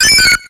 Audio / SE / Cries / WOOPER.ogg